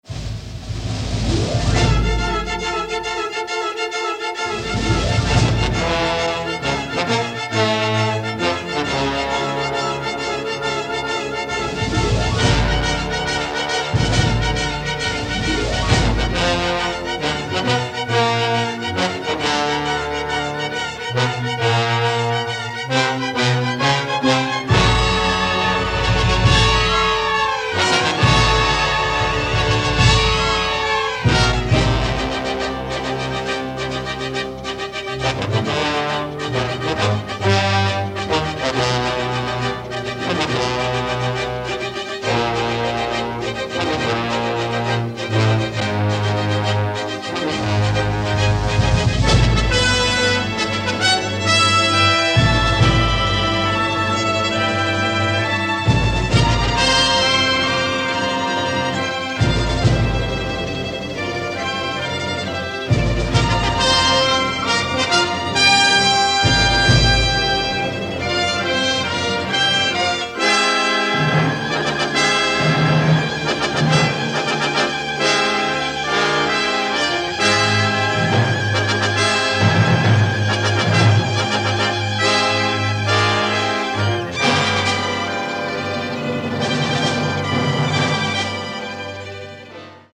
rousing orchestral work